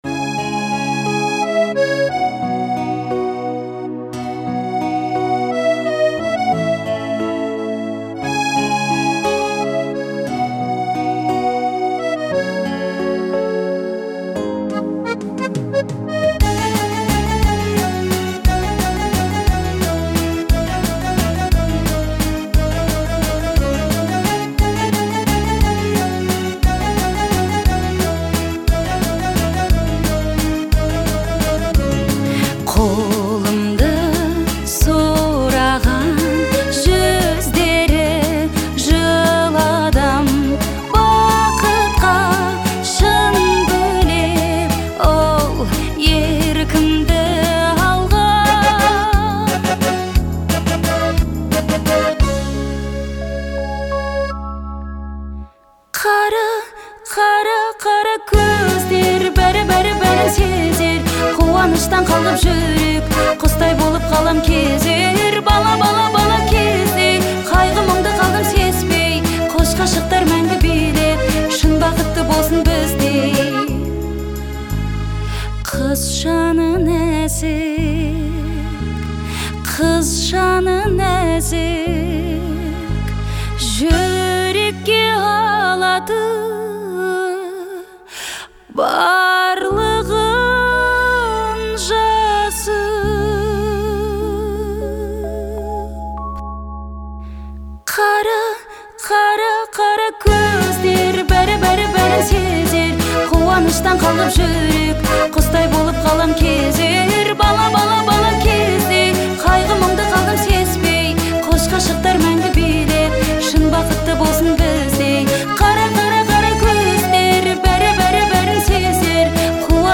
это мелодичная казахская поп-песня